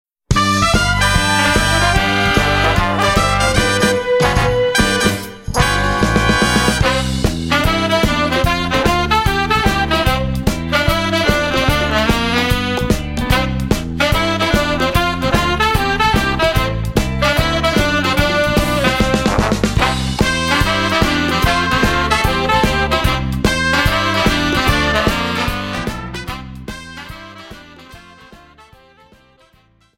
JIVE